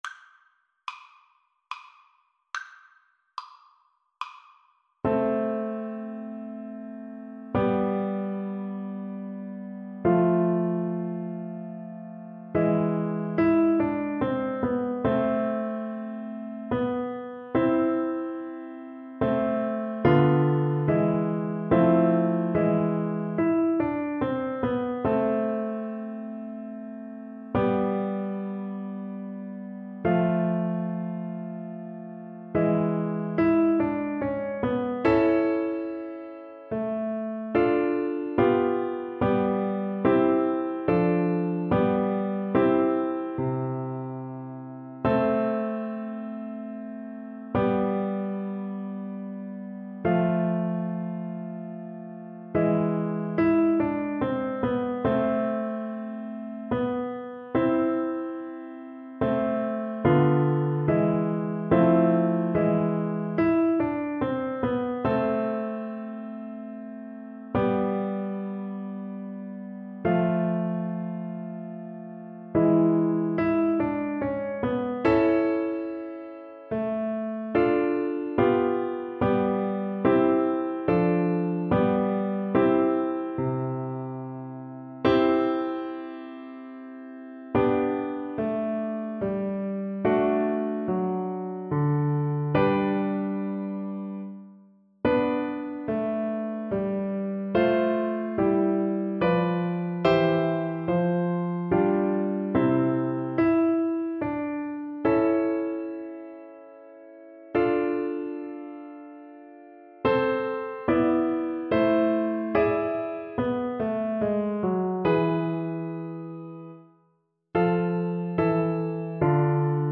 3/4 (View more 3/4 Music)
=100 Moderato
Classical (View more Classical Flute Music)